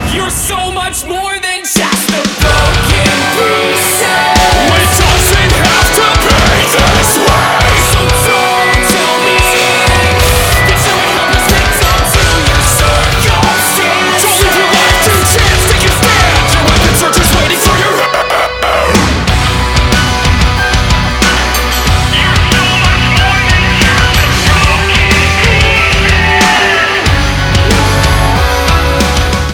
• Качество: 192, Stereo
Классный rap-core